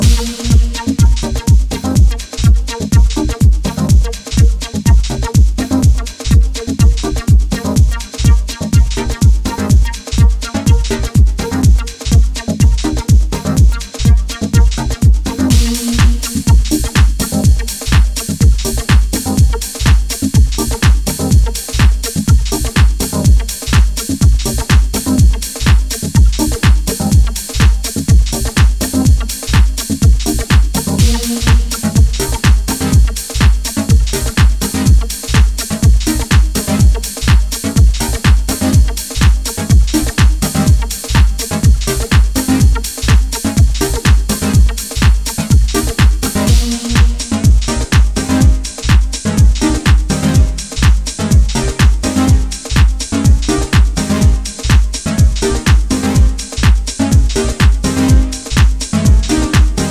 自身のルーツであるラテン由来のグルーヴとメロディアスなシンセワークが掛け合う
ハウスに流れるラテンの遺伝子をモダンなタッチで強調。